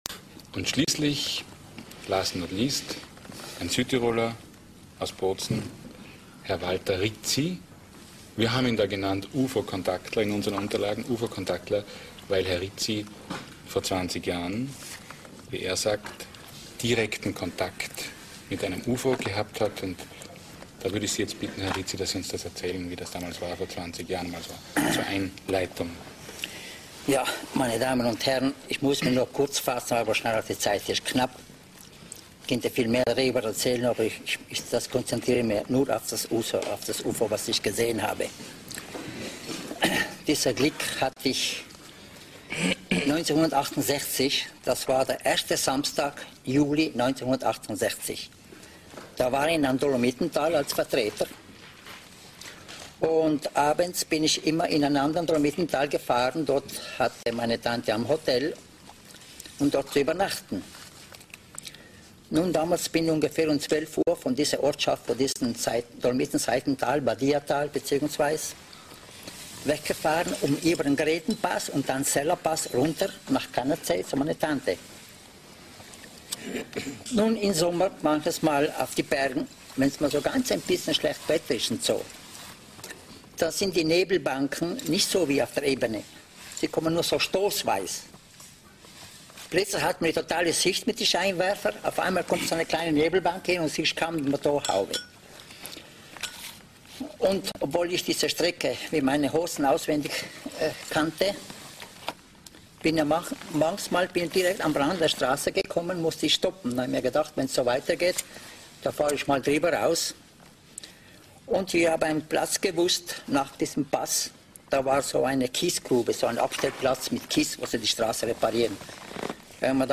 Dieses sehr seltene Interview findet man kaum noch, deswegen wurde es hier archiviert.